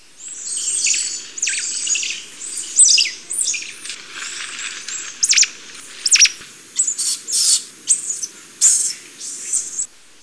Squirrel Monkey
squirrelmonkey_sound.wav